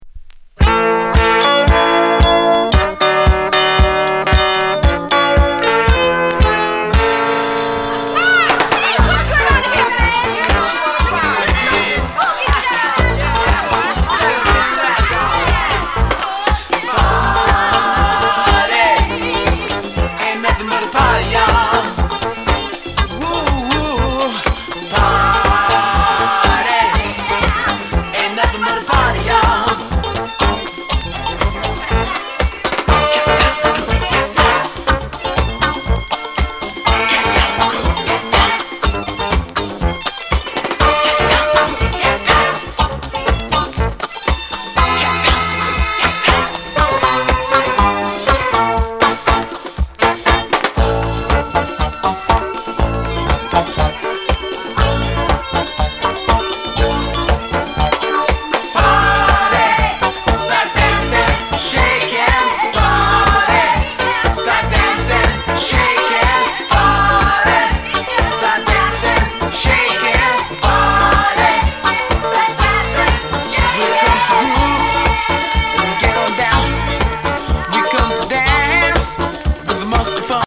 Genre:  Soul/Reggae